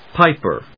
píp・er
• / ˈpaɪpɝ(米国英語)
• / ˈpaɪpɜ:(英国英語)